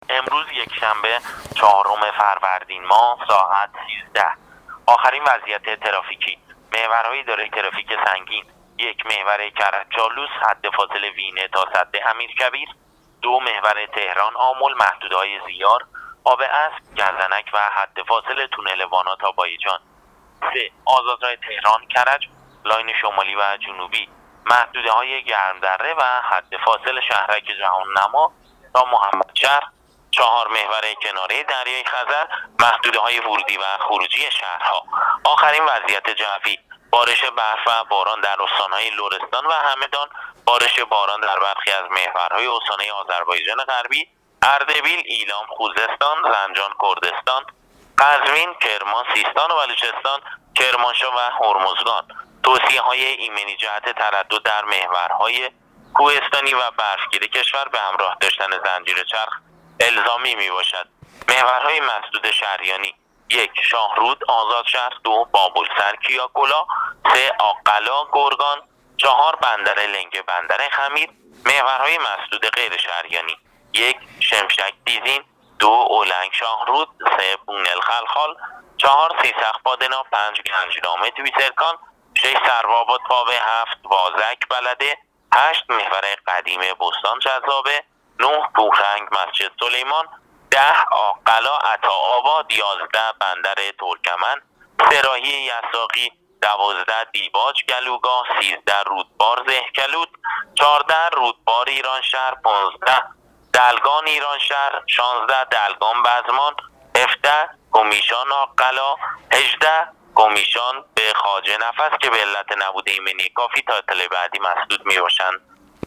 رادیو اینترنتی پایگاه خبری وزارت راه و شهرسازی